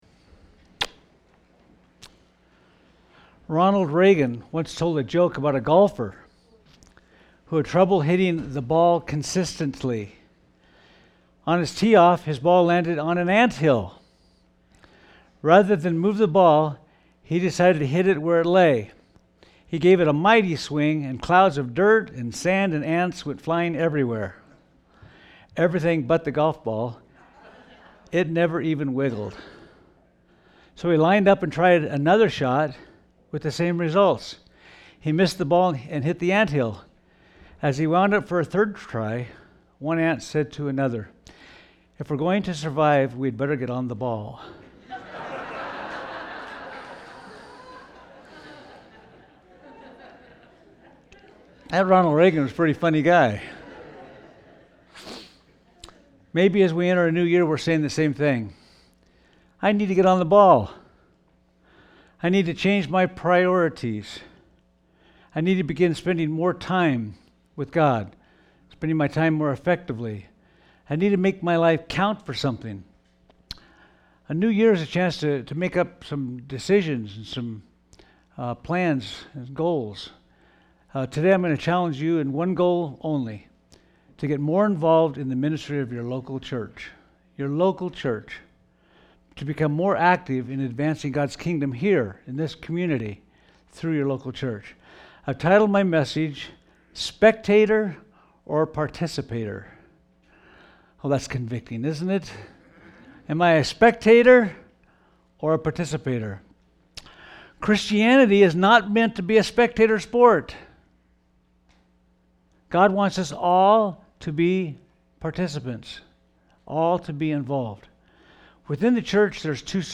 A message from the series "Topical." psalms 23